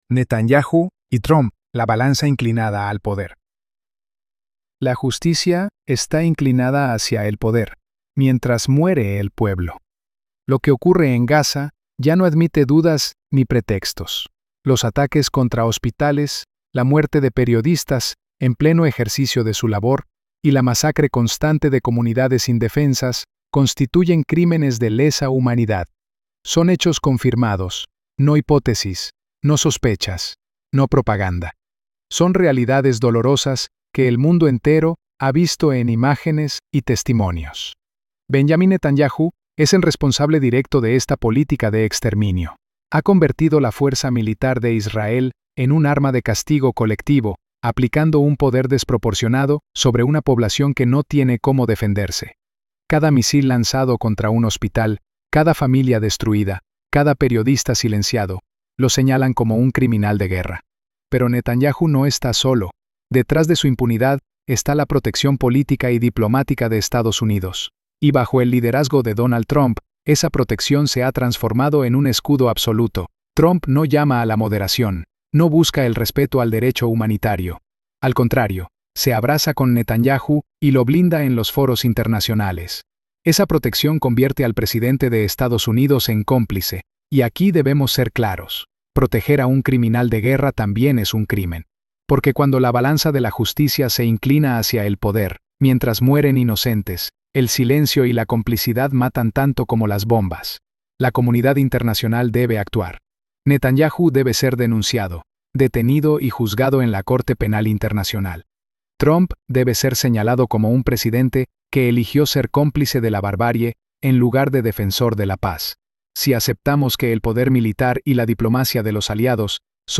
Audio editorial • Fondo negro • Letras rojas brillantes • Sombra roja luminosa